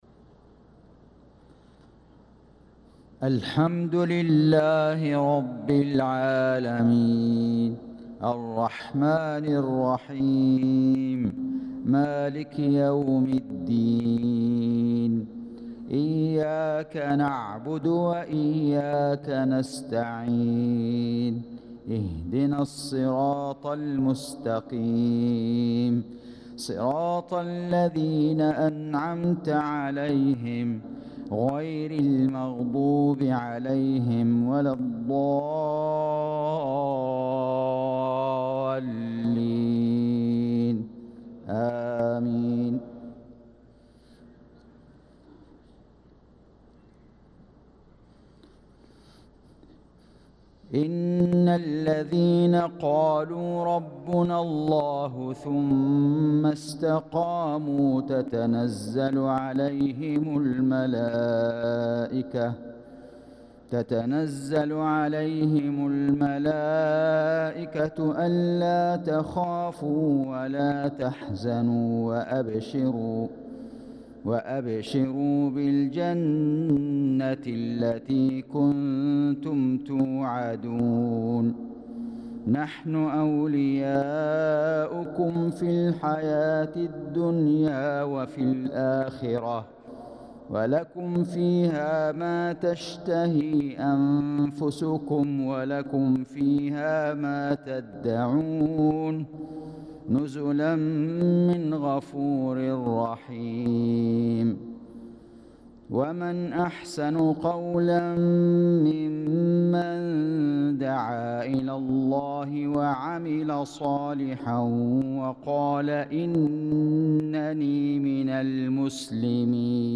صلاة المغرب للقارئ فيصل غزاوي 22 ذو الحجة 1445 هـ
تِلَاوَات الْحَرَمَيْن .